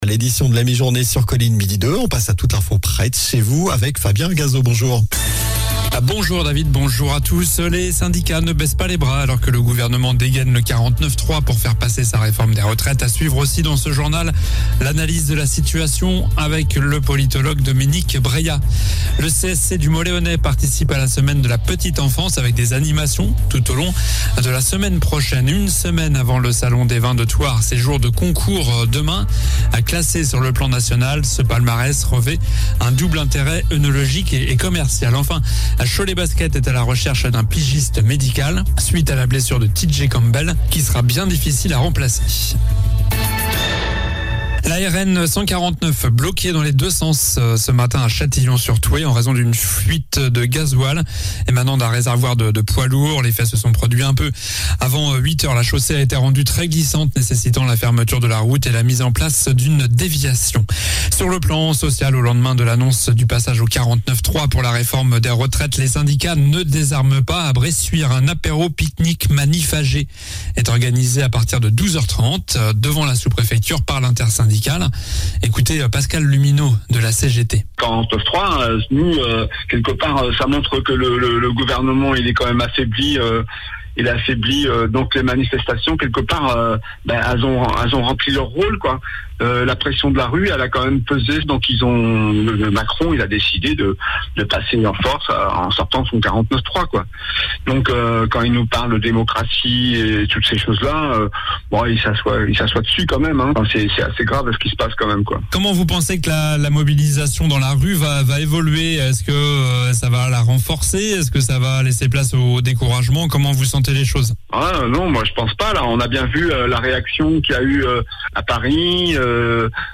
Journal du vendredi 17 mars (midi)